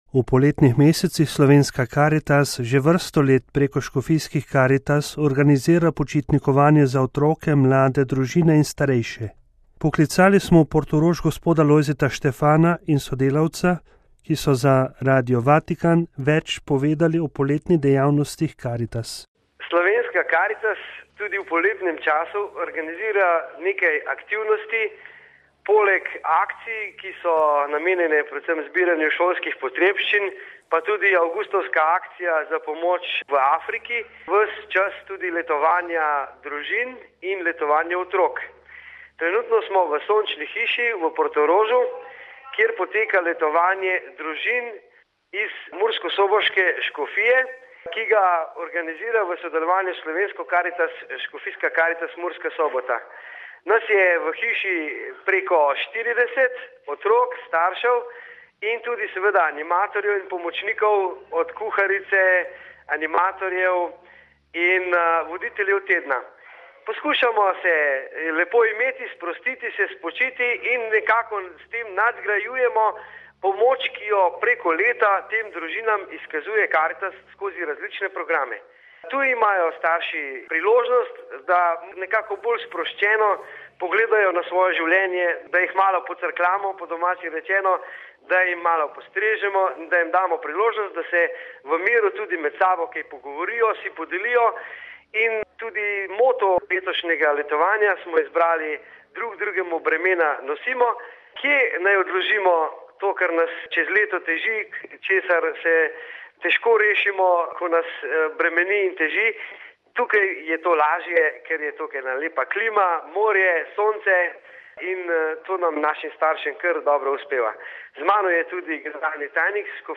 SLOVENIJA (torek, 2. avgust 2011, RV) – V poletnih mesecih Slovenska Karitas že vrsto let preko škofijskih Karitas organizira počitnikovanje za otroke, mlade, družine in starejše. Poklicali smo v Portorož